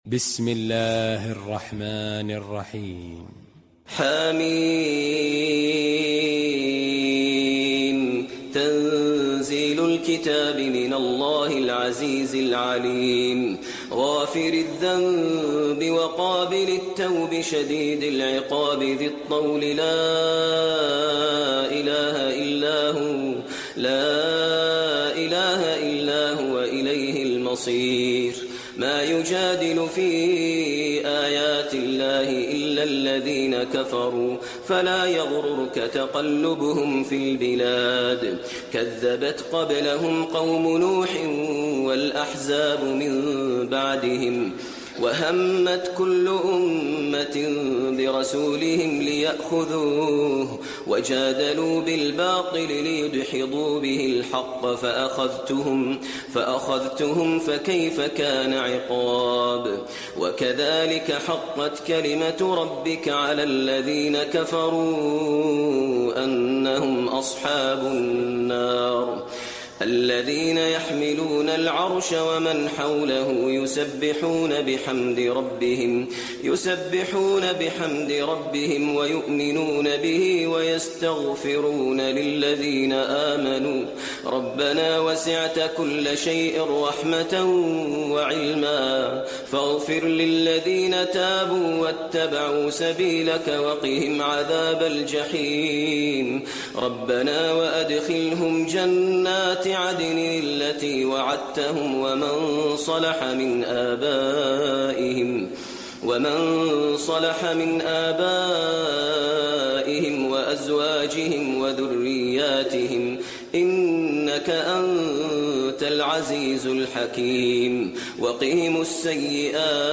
المكان: المسجد النبوي غافر